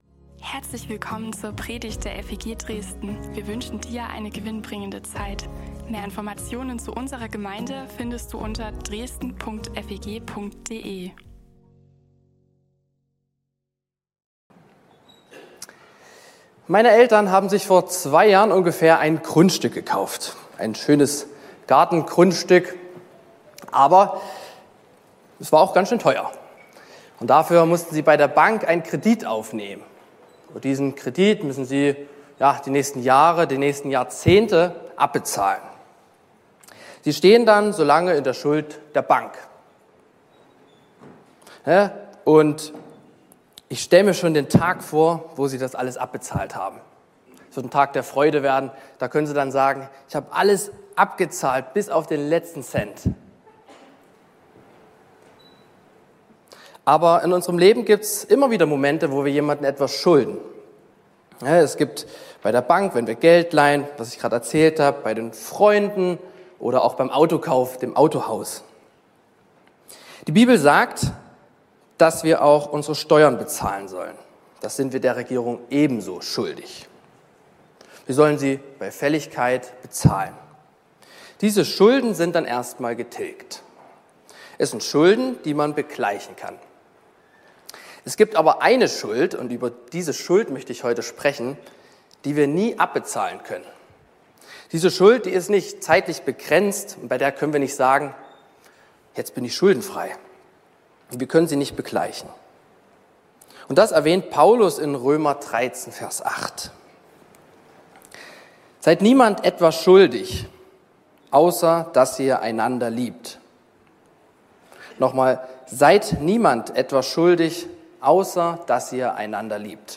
Predigten und mehr